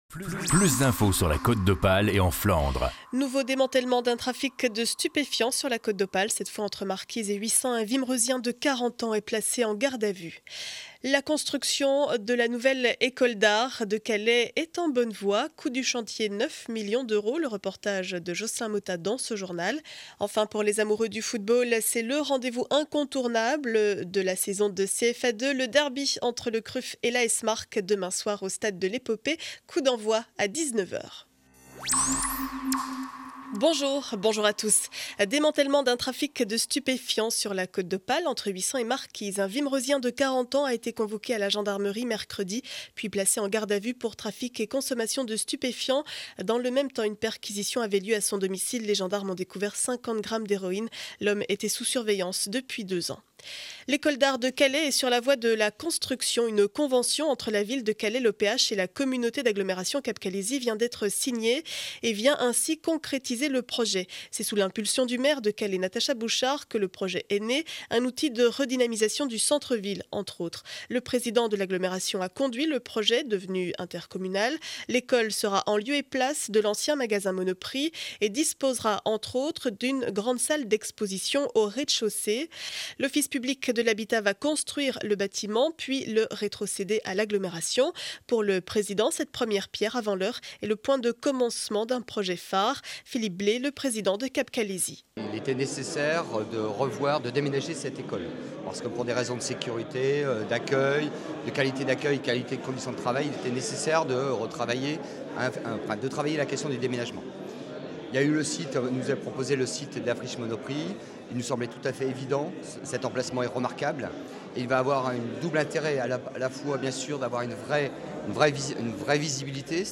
Journal du vendredi 13 avril 2012 7 heures 30 édition du Calaisis.